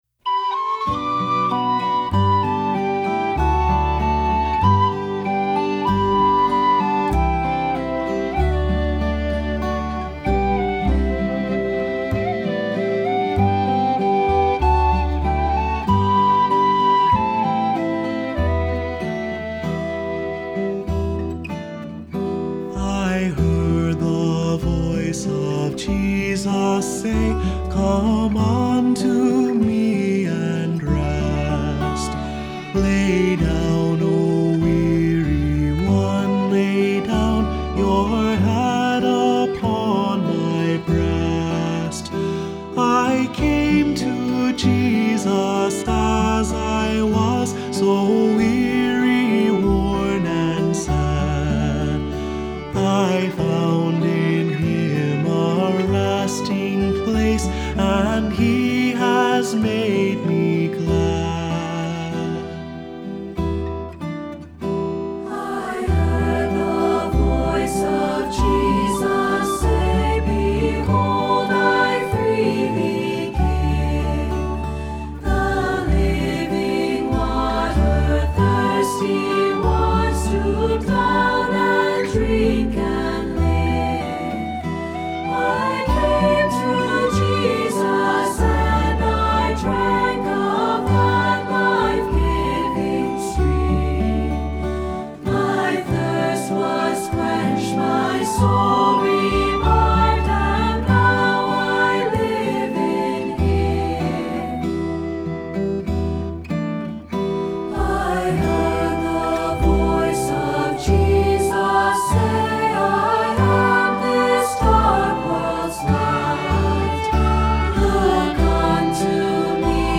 Accompaniment:      Piano
Music Category:      Christian